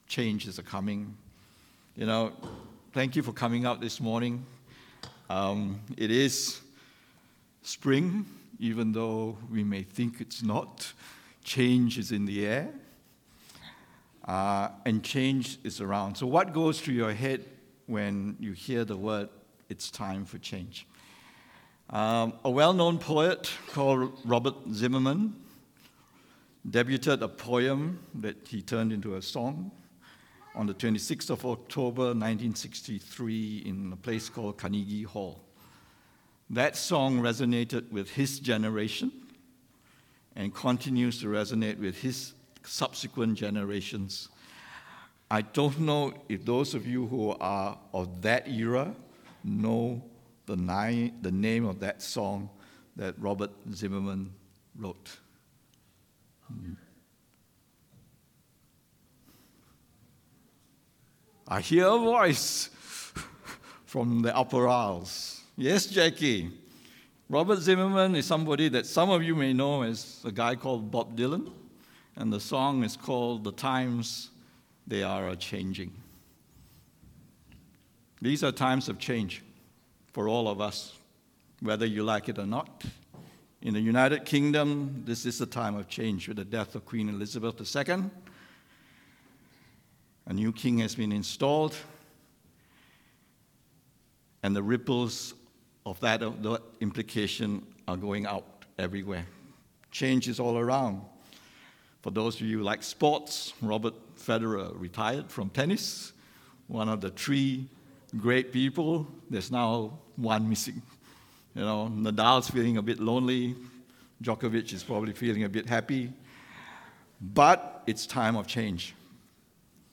English Sermons | Casey Life International Church (CLIC)
English Worship Service - 18th of September 2022